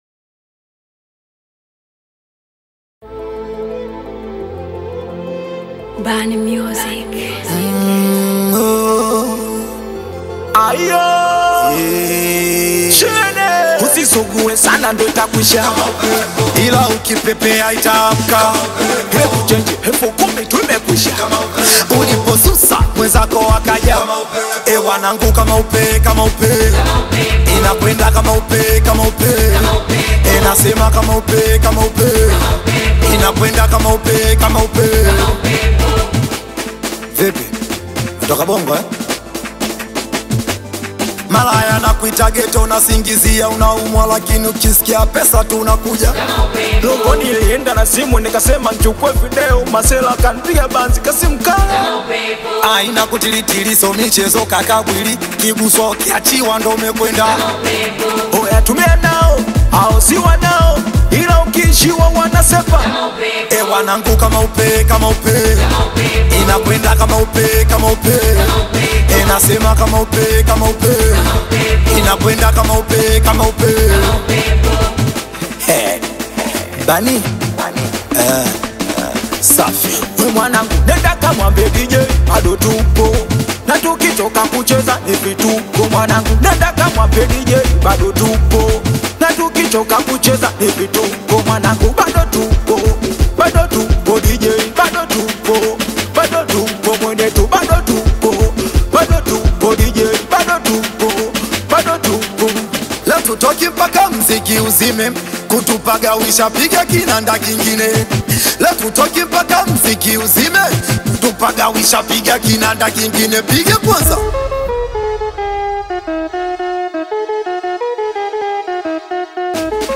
Singeli
signature fast-paced vocals